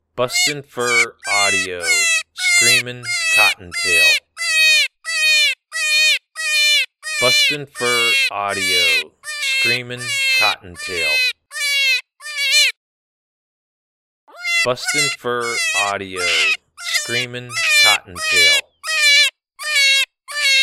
Adult Cottontail in distress.
BFA Screamin Cottontail Sample.mp3